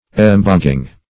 Search Result for " emboguing" : The Collaborative International Dictionary of English v.0.48: Emboguing \Em*bo"guing\, n. The mouth of a river, or place where its waters are discharged.